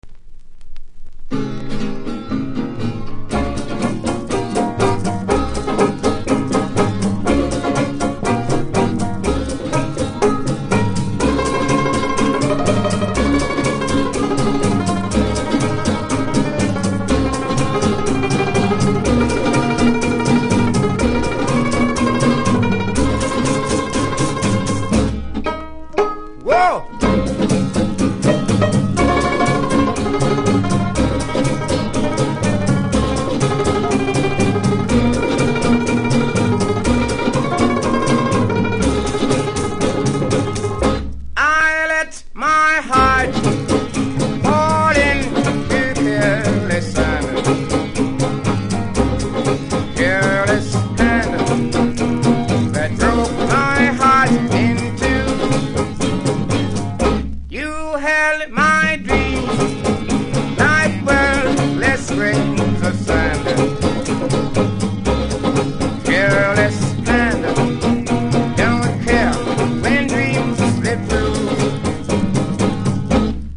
キズはそこそこありますがノイズは少なく良好盤です。